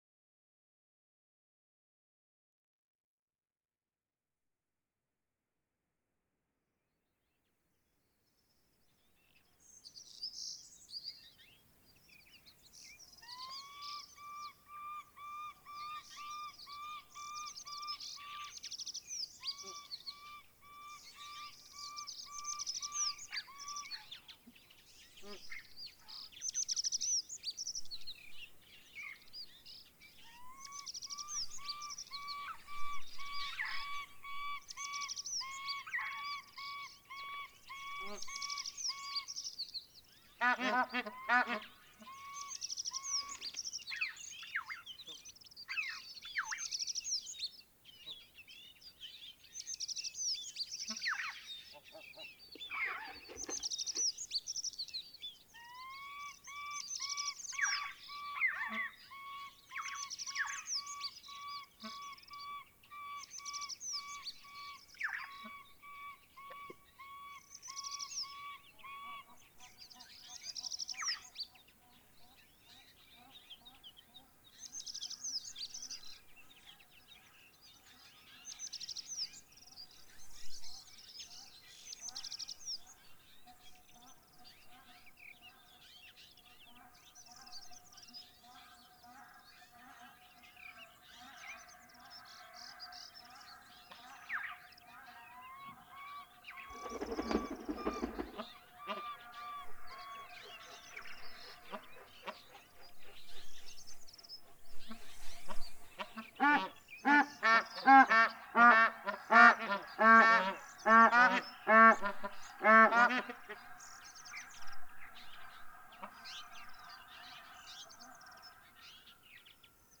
Montane steppe
PFR09326, Bar-headed Goose, territorial fights
Bayankhongorijn Khukh Nuur, Mongolia
PFR09291, Altai Snowcock, song, excitement calls
(Asian) Red-billed Chough, calls